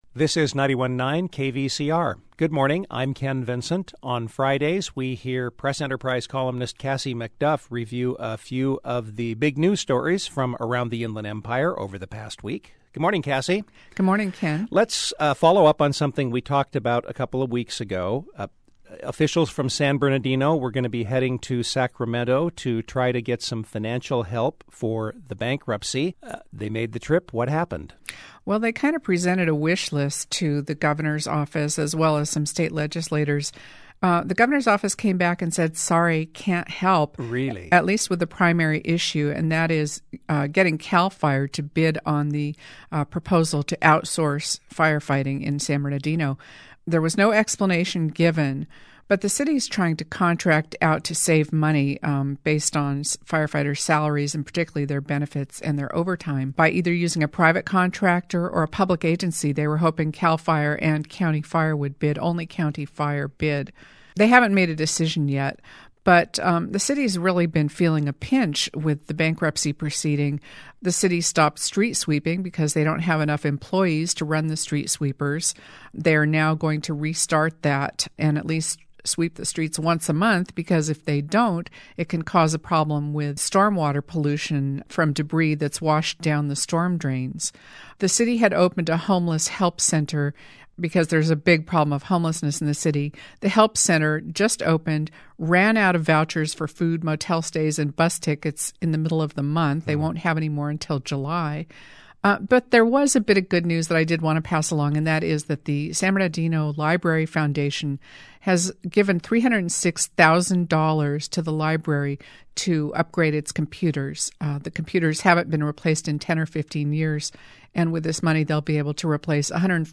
[RECORDED THU 6/25 FOR AIR FRI 6/26]